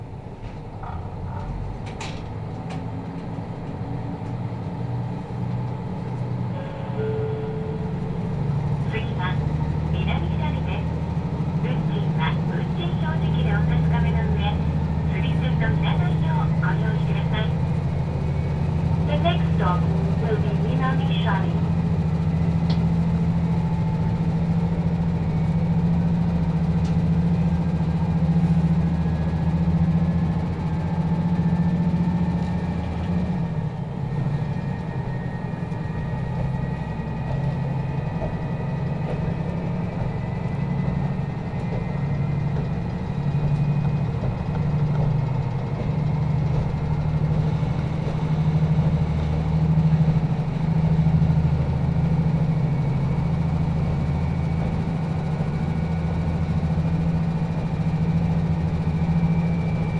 釧網本線の音の旅｜南斜里駅 廃止直前の停車と走行音
釧網本線・南斜里駅（2021年廃止）に停車するキハ54系釧路行き始発列車の音を収録。知床斜里から続く通学列車の車内風景と、静かに役目を終えようとする無人駅の記録を音で残した音鉄作品です。
釧網本線の音の旅｜南斜里駅-廃止直前の停車と走